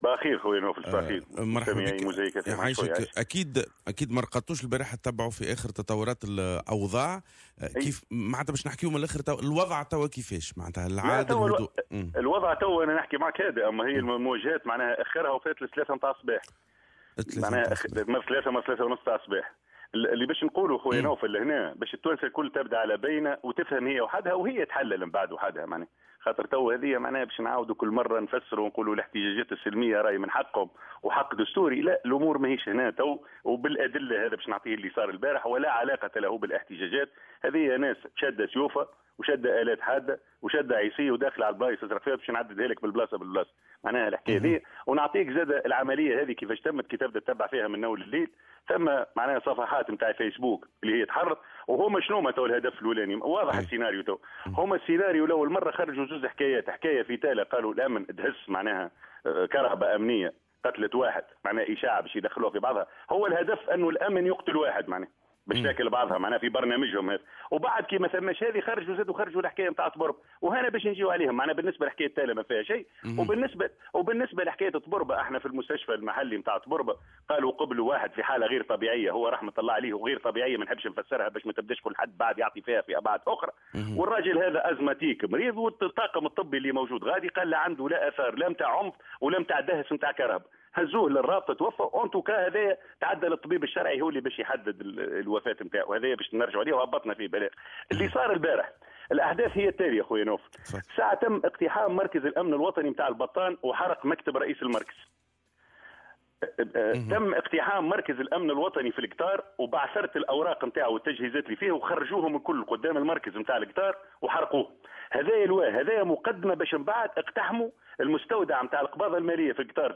est intervenu hier tard dans la nuit, sur Mosaïque FM.